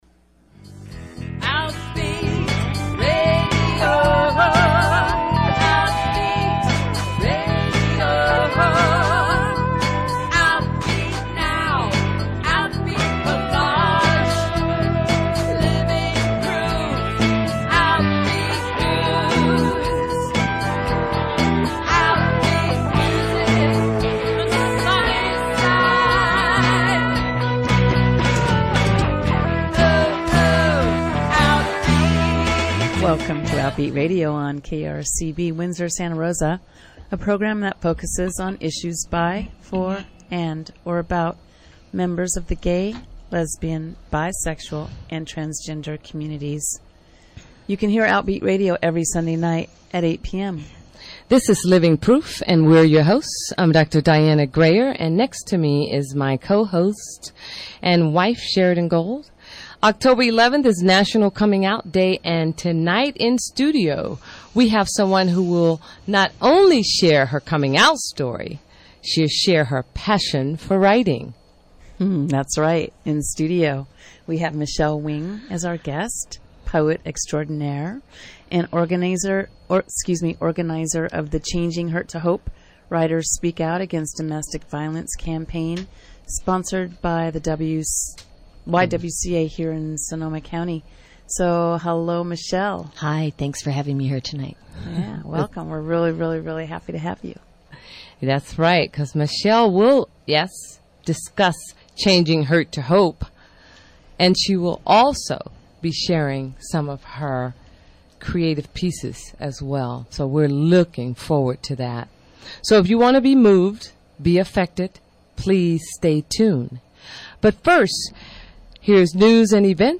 Outbeat Radio is a weekly radio program for and about the lesbian, gay, bisexual and transgender community in the California North Bay airing on KRCB Radio 91.1 FM